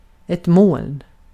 Uttal
Synonymer sky rök virvel svärm rökmoln Uttal Okänd accent: IPA: /moːln/ IPA: /mɔln/ Ordet hittades på dessa språk: svenska Översättning Substantiv 1. bulut Artikel: ett .